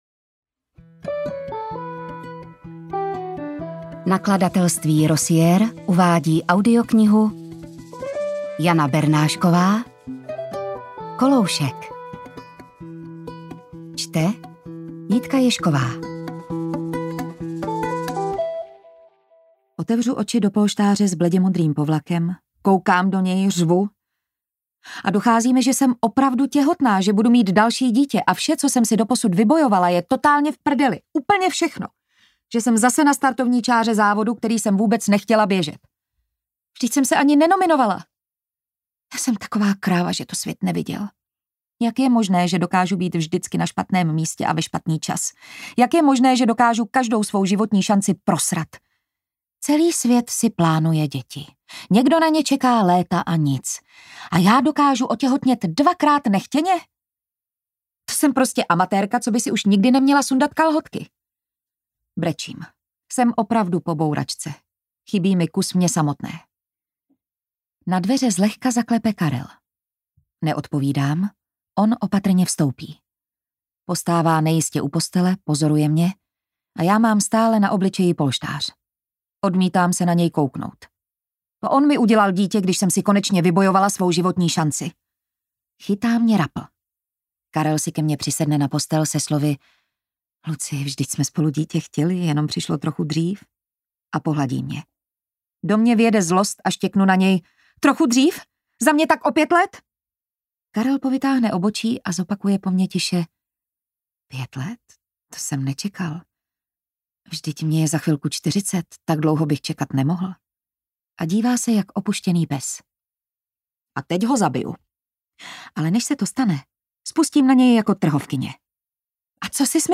Koloušek audiokniha
Ukázka z knihy